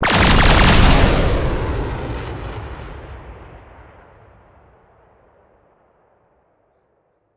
TheExperienceLight / sounds / Cosmic Rage / ships / Combat / weapons / salvotorpf.wav